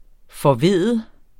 Udtale [ fʌˈveðˀə ]